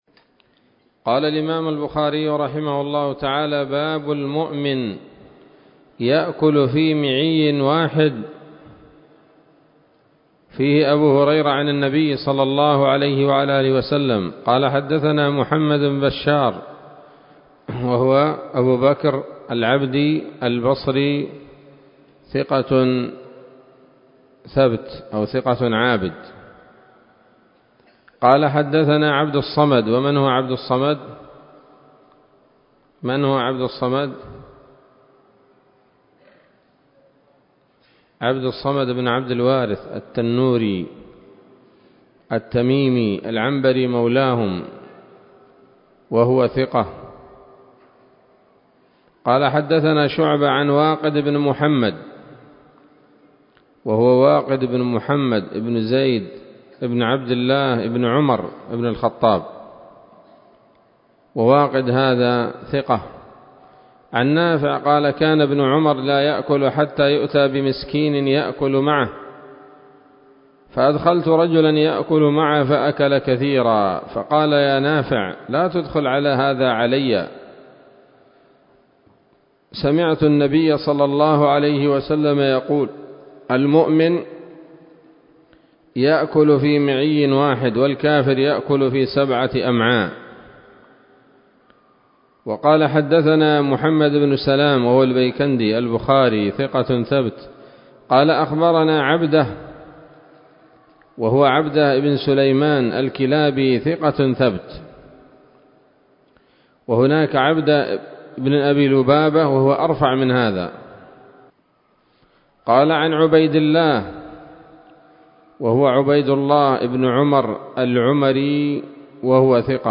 الدرس التاسع من كتاب الأطعمة من صحيح الإمام البخاري